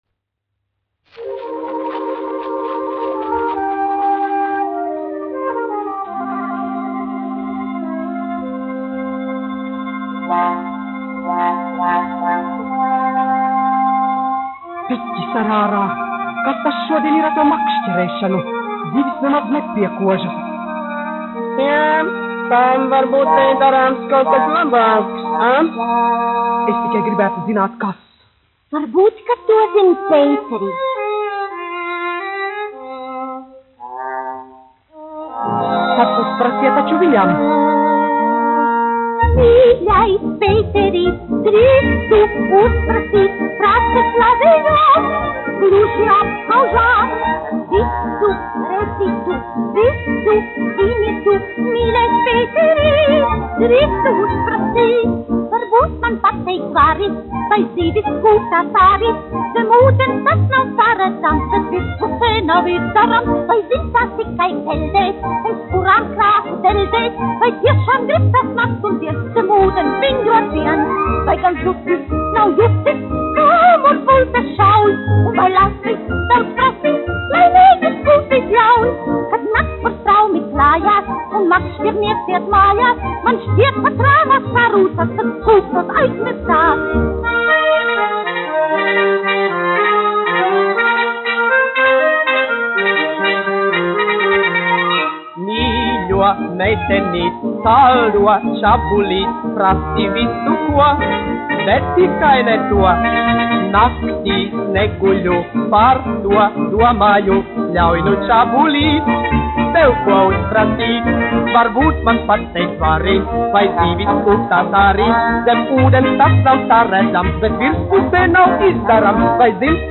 1 skpl. : analogs, 78 apgr/min, mono ; 25 cm
Fokstroti
Populārā mūzika